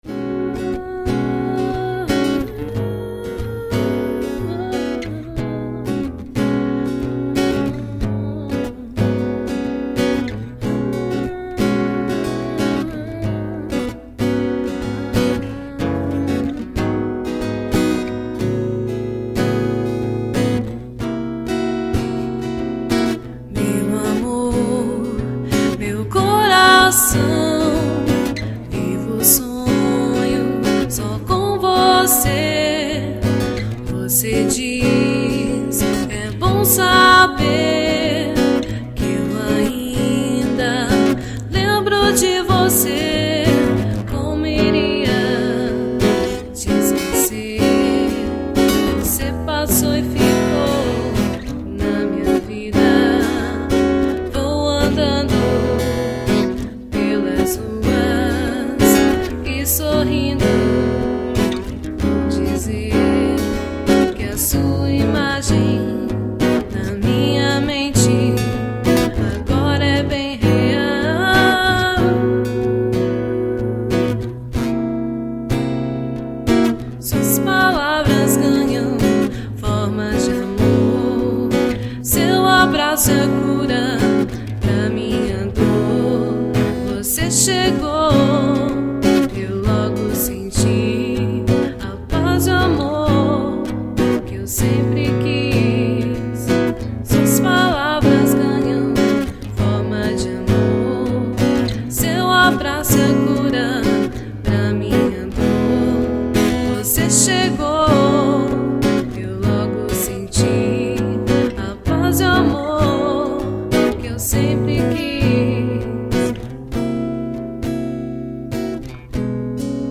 violão